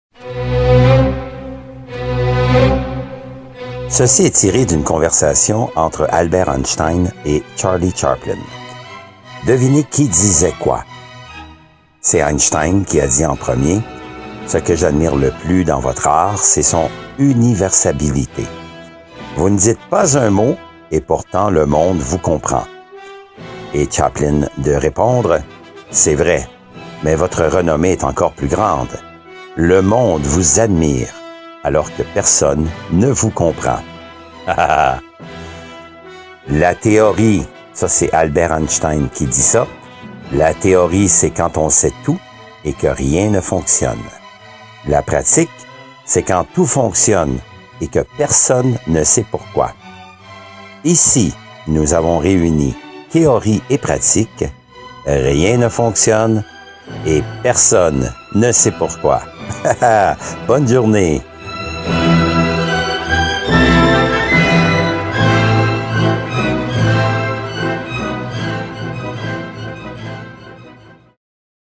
Musique de fond; Le canon de Pachelbel ( violons en 432 htz )
( la qualité sonore est variable… )